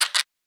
Scratch_v5_wav.wav